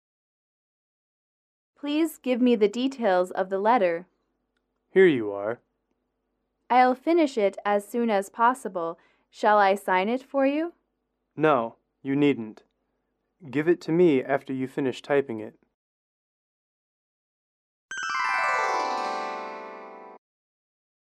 英语主题情景短对话15-3：商务信函（MP3）
英语口语情景短对话15-3：商务信函（MP3）